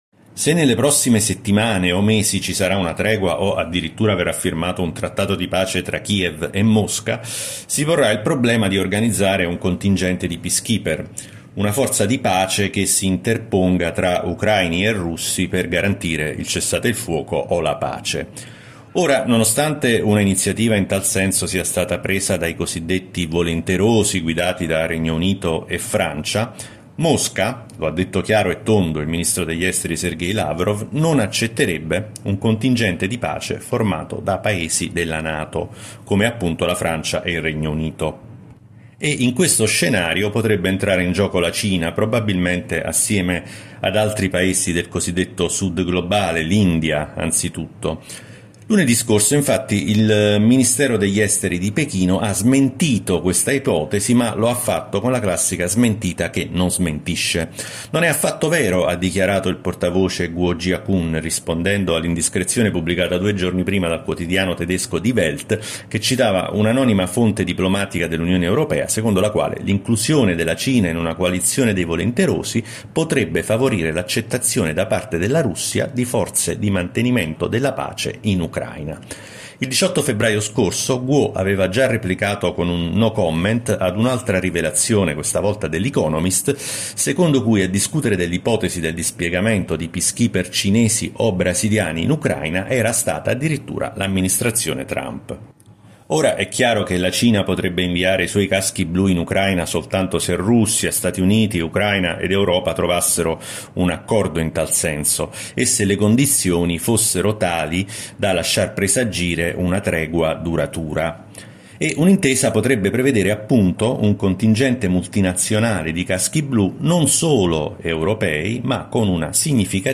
I particolari nella corrispondenza da Shanghai